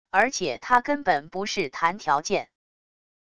而且他根本不是谈条件wav音频生成系统WAV Audio Player